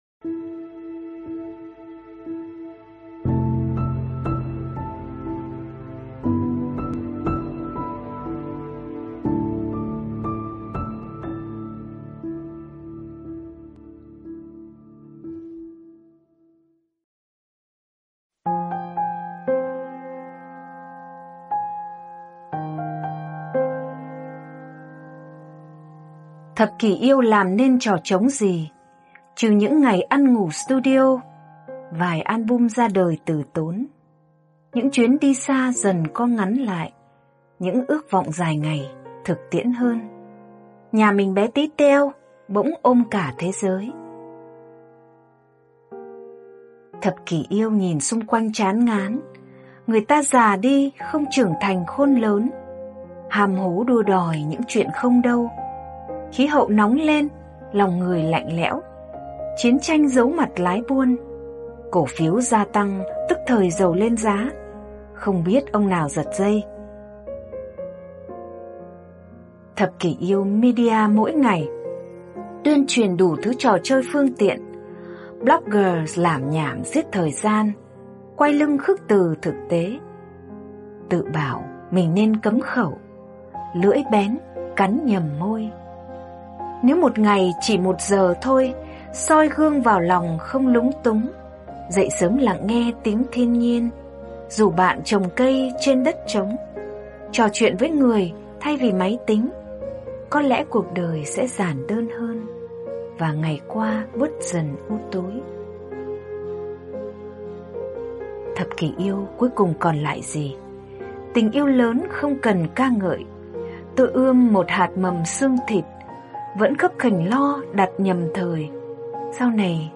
Thích Minh Niệm - Mp3 thuyết pháp